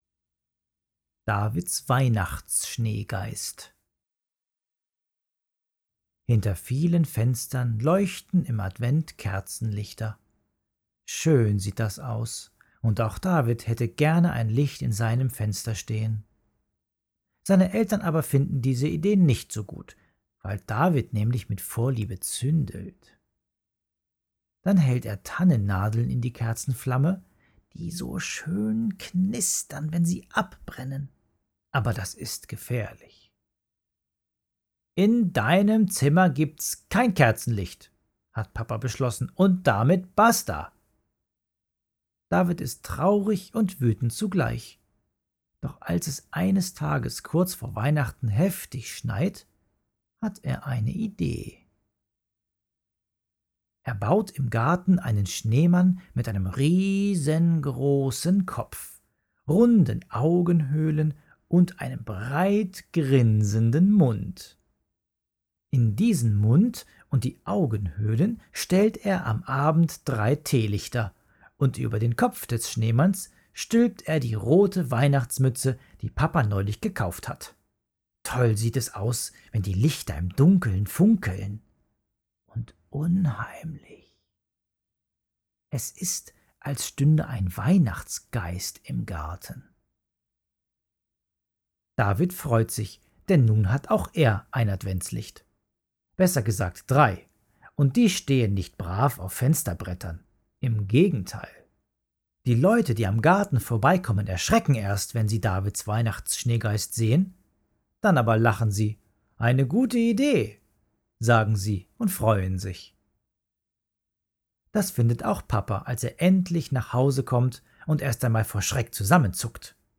Fröhliche Adventsgeschichte – Von Schneegeistern, Gruselgeschichten, Basteleien und einem verhinderten Weihnachtsmann